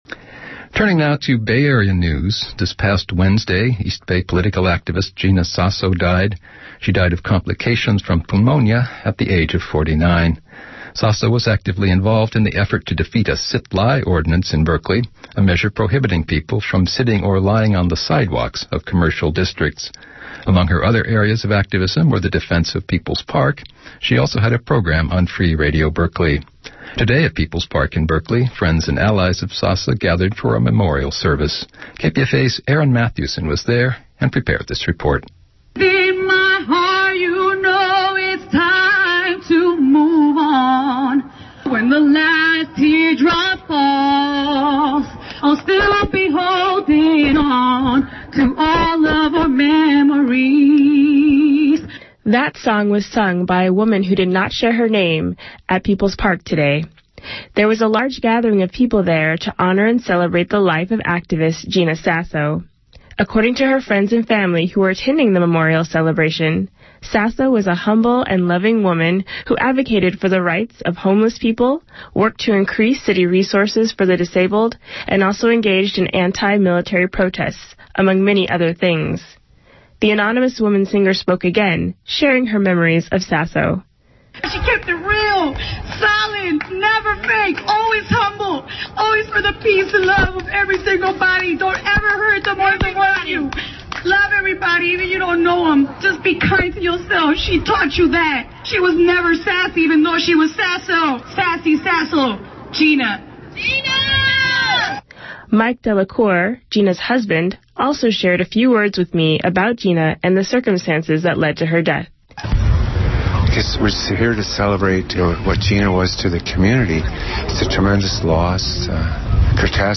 KPFA Evening News coverage